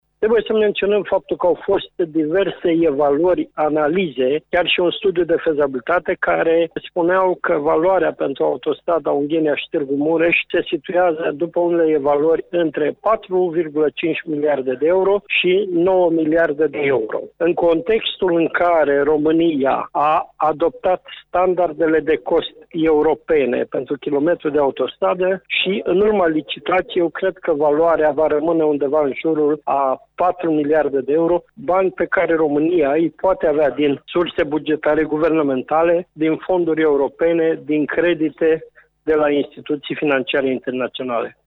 Petru Movilă a declarat că acest proiect va fi readus şi în atenţia membrilor Guvernului, care vor fi prezenţi la Iaşi, la sfârşitul lunii noiembrie, pentru celebrarea Centenarului Marii Uniri: